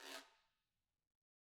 Guiro-Fast_v1_Sum.wav